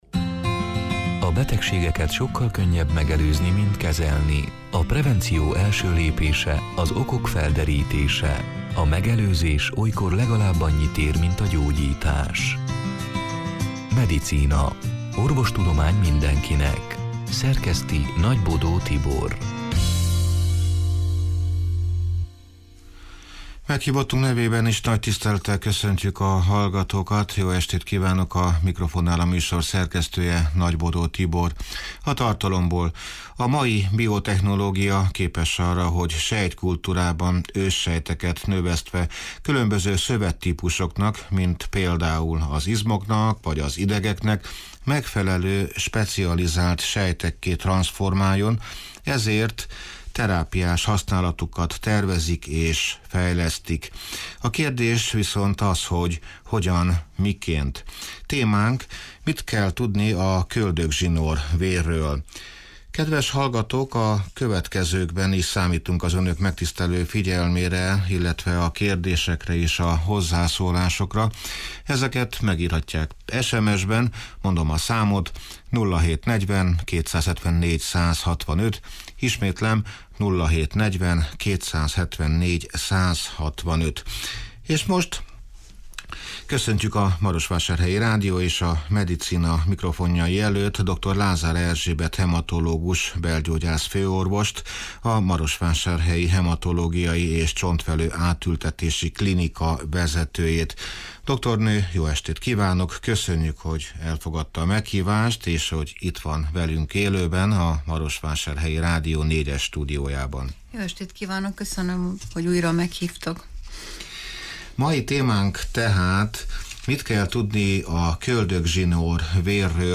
(elhangzott: 2023. augusztus 9-én, szerdán este nyolc órától élőben)